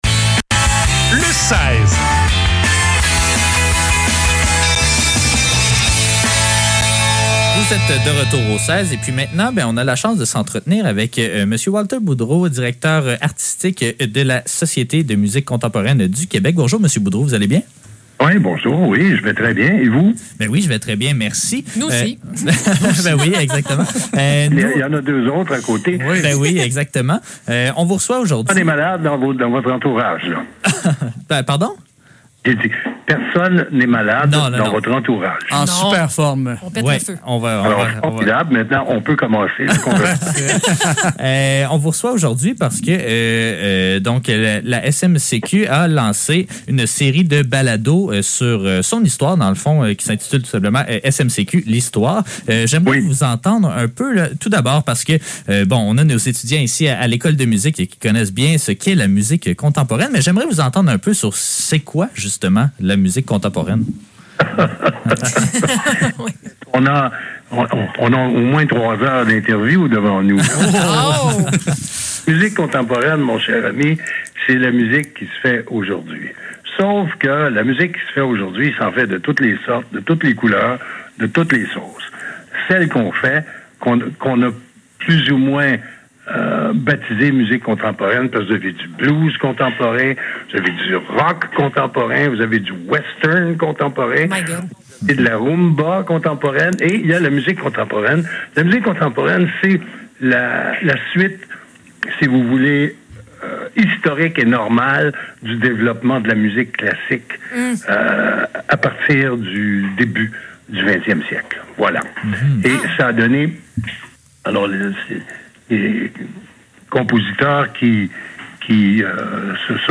Le seize - Entrevue avec Walter Boudreau - 21 mars 2022